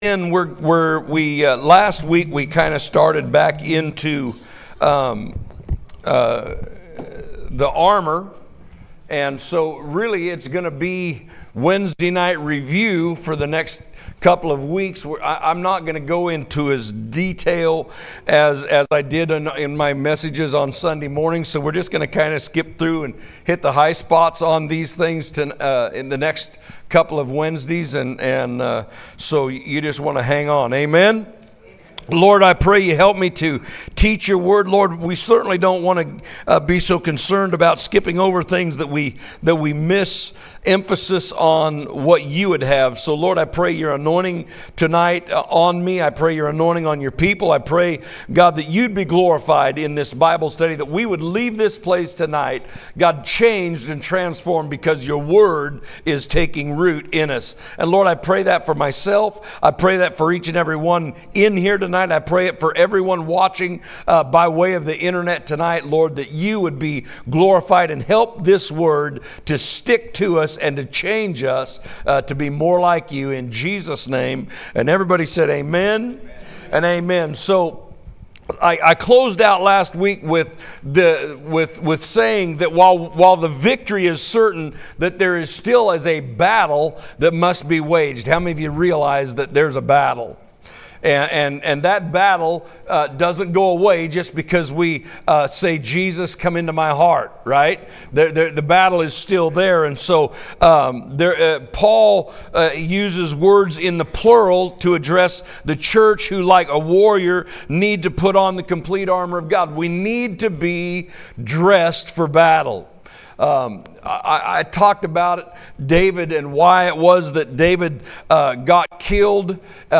Midweek Service February 26, 2025 – Discipleship Training Part 58
Recent Sermons